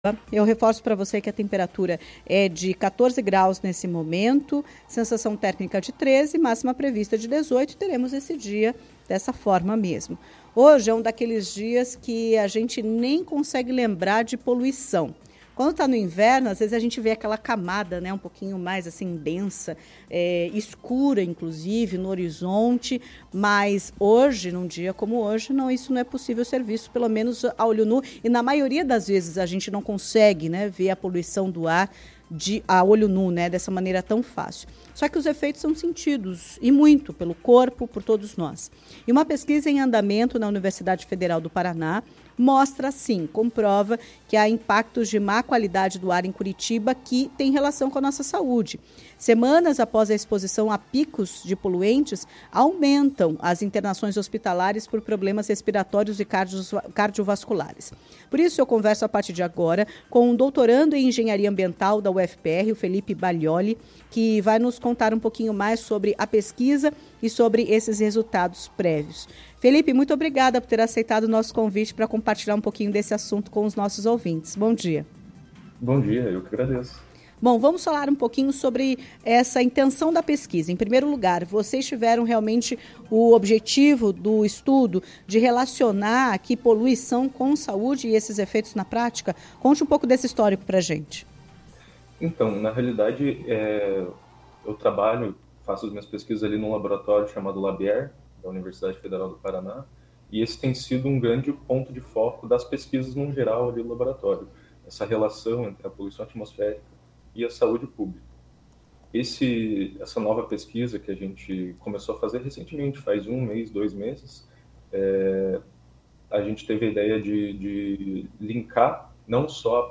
Em entrevista à CBN Curitiba